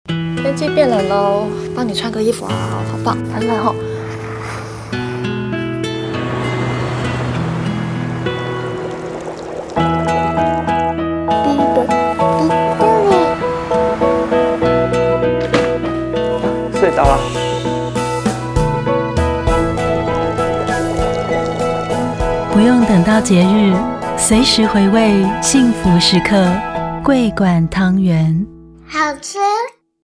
國語配音 女性配音員
✔ 聲音具有親和力，極具穿透力與辨識度